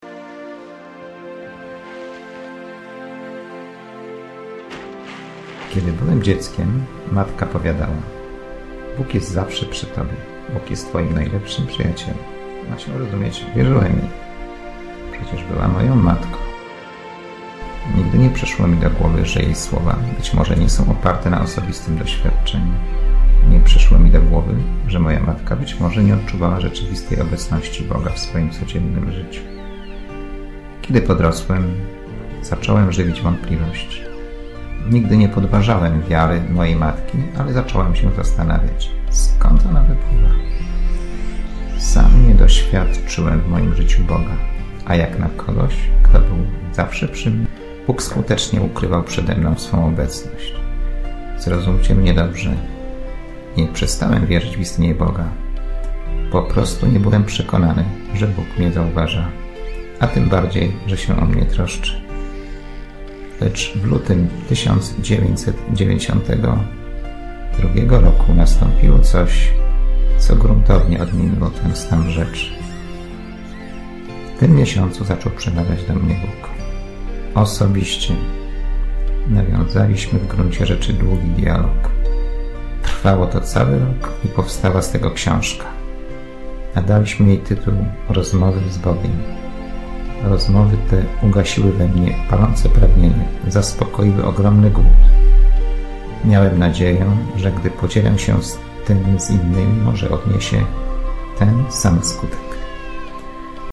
Znakomita muzyka i s�owa…